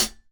ClosedHH Koopa 1.wav